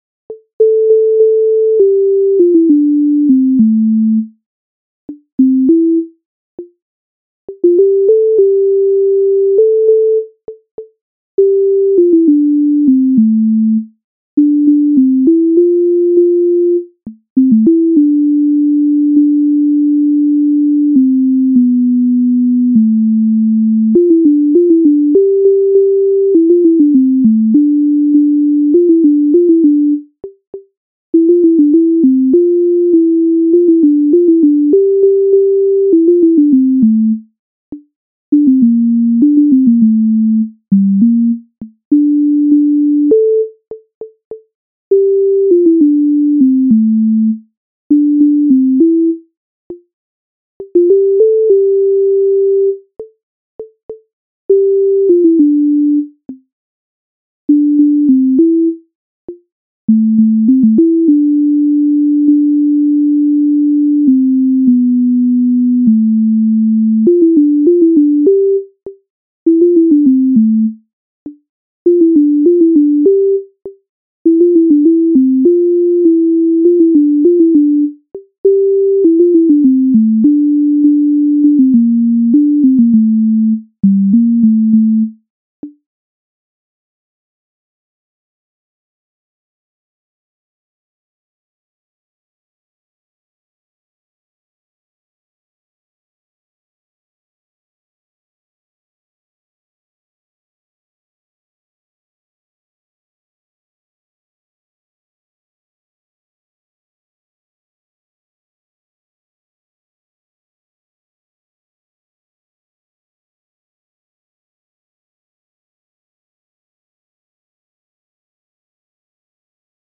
MIDI файл завантажено в тональності F-dur